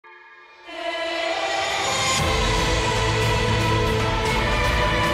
Category: Anime Soundboard